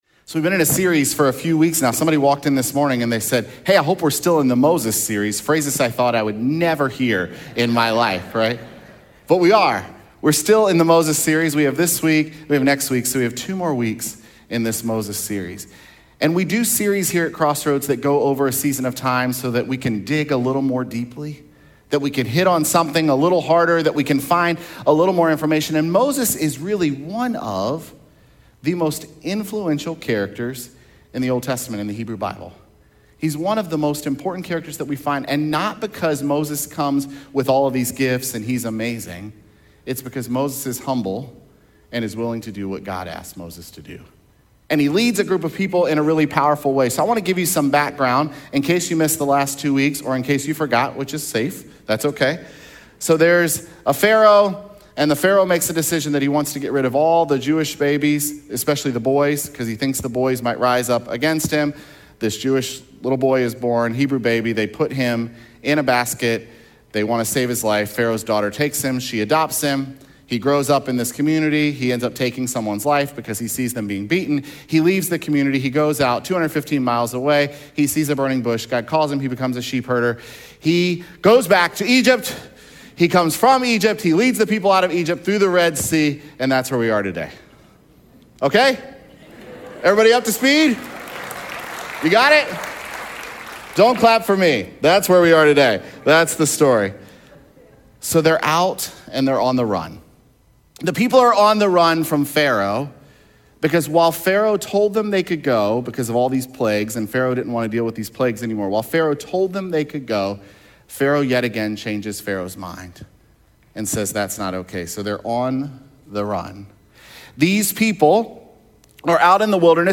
aug20sermon.mp3